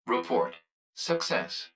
042_Report_Success.wav